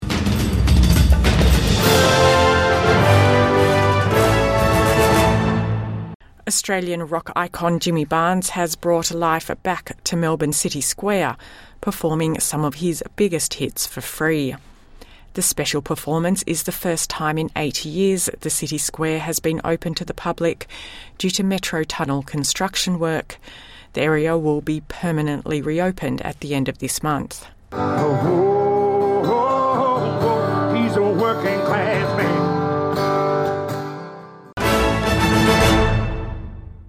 Jimmy Barnes performs free concert in Melbourne City Square